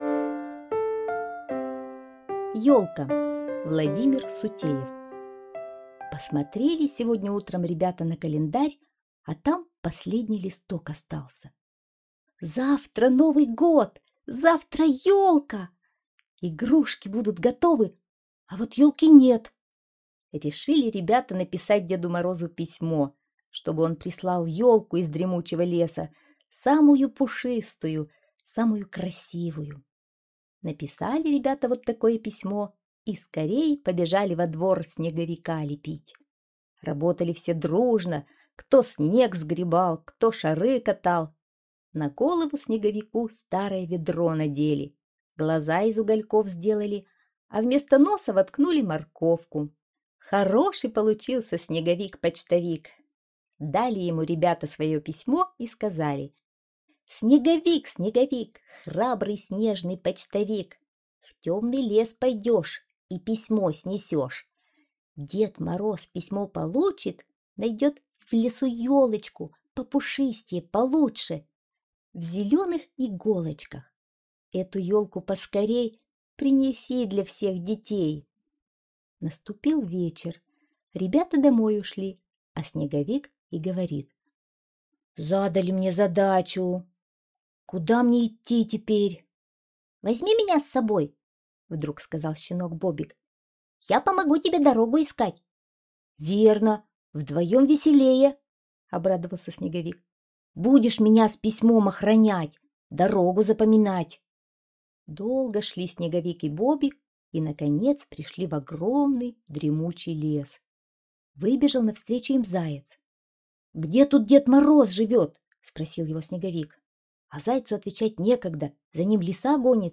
Аудиосказка «Елка»